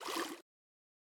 PenguinSplash-005.wav